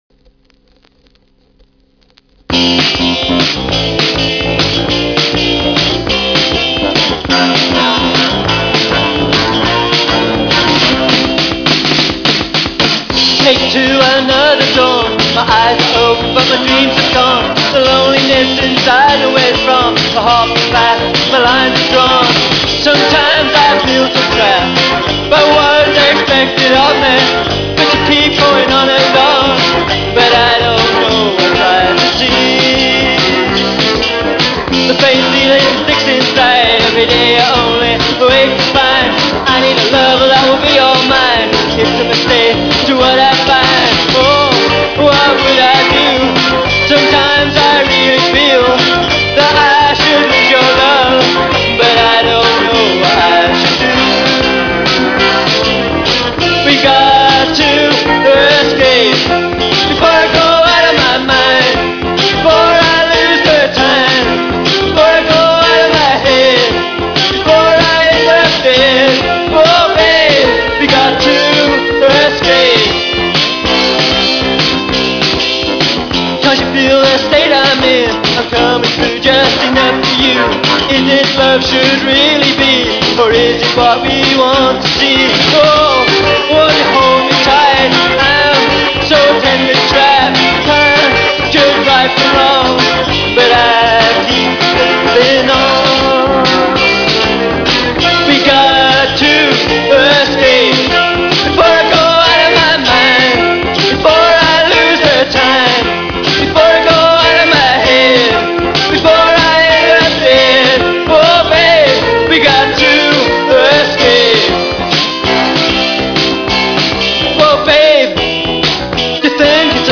きいても80〜82年の音に